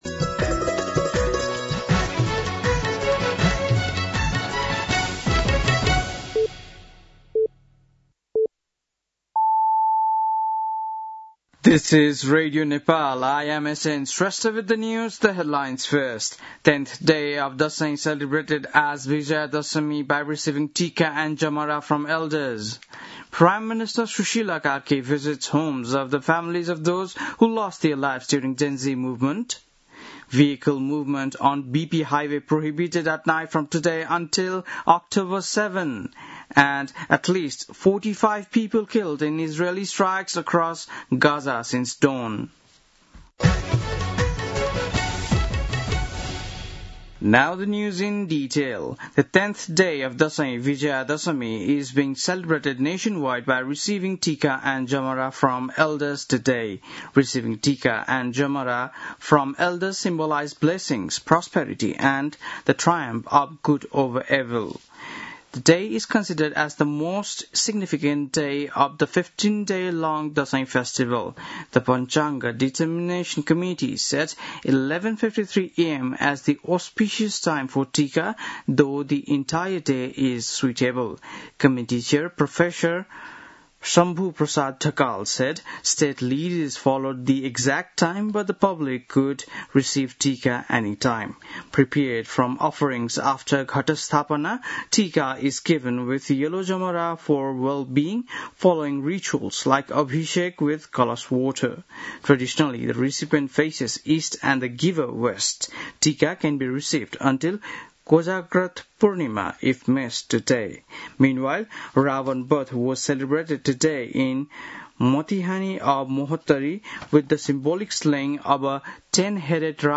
बेलुकी ८ बजेको अङ्ग्रेजी समाचार : १६ असोज , २०८२
8-pm-news-6-16.mp3